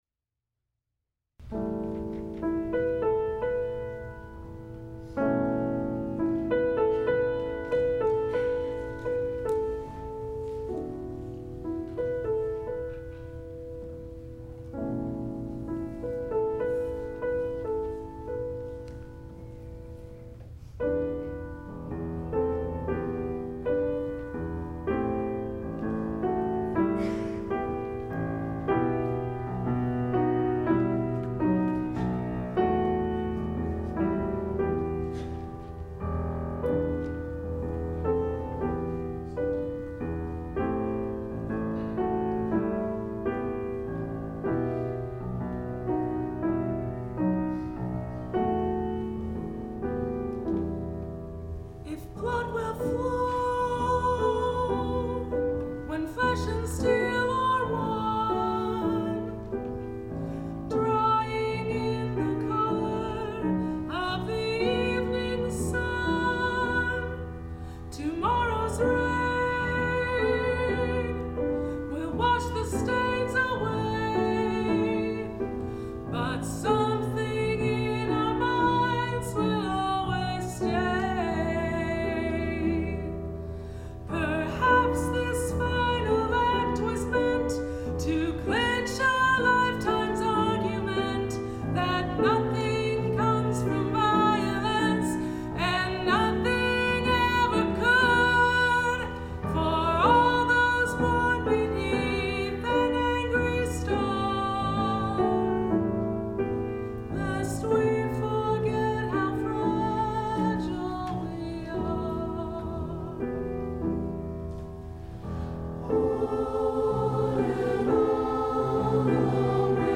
SAB and Solo with piano
Easy arrangement - mostly two parts - treble and bass with
3-part a cappella last refrain